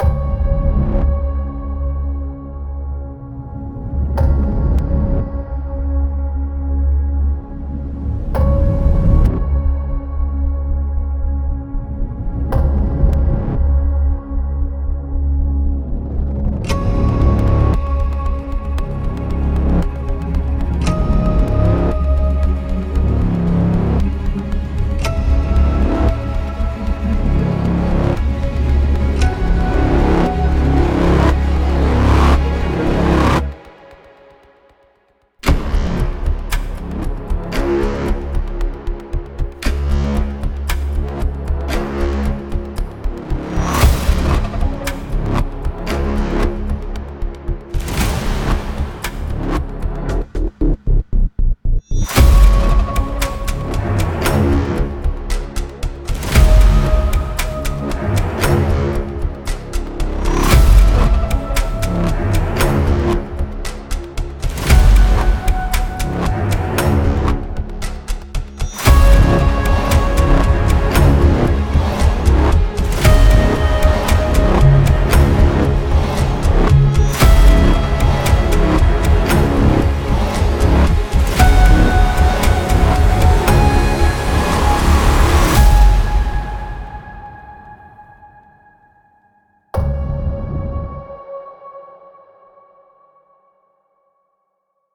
royalty free music
Genre: trailer, production.